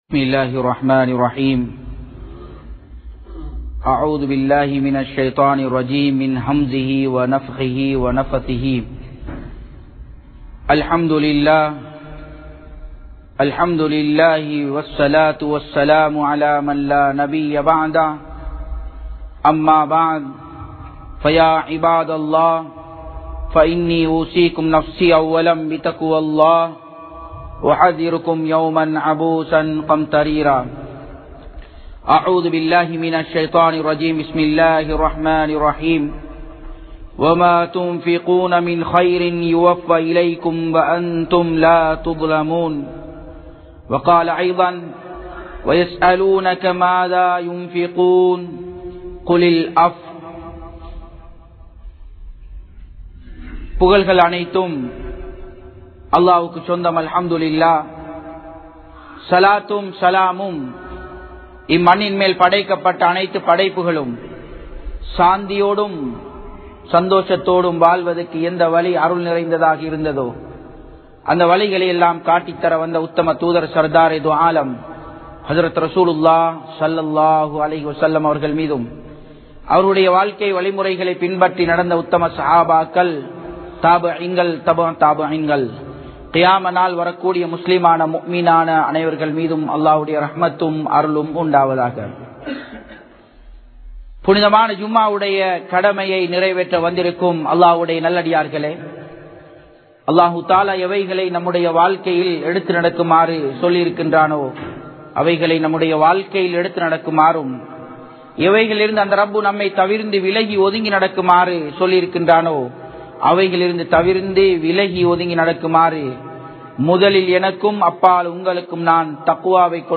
Ketta Maranaththai Thadukkam Sathaqa (கெட்ட மரணத்தை தடுக்கும் ஸதகா) | Audio Bayans | All Ceylon Muslim Youth Community | Addalaichenai